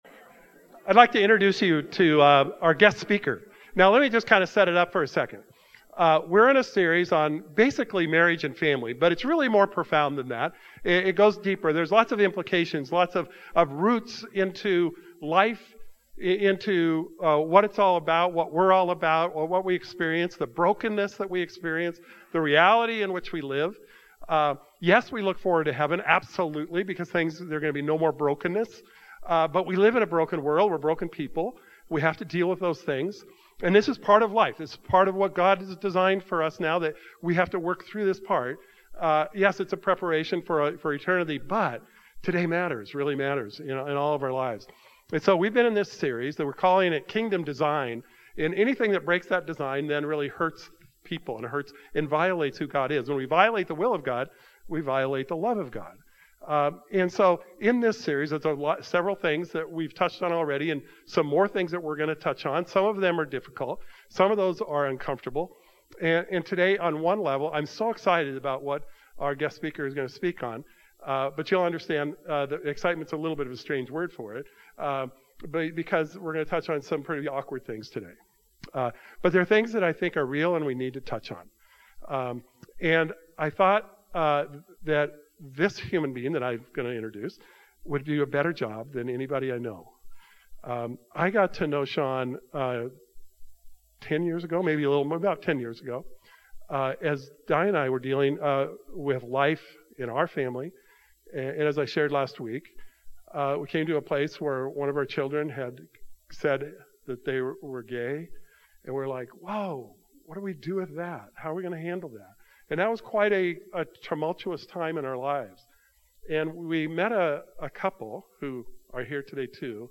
2023 Guest Speaker Truth Matthew 0 Comments Show Audio Player Save Audio Save PDF This morning’s message is brought to us by a guest speaker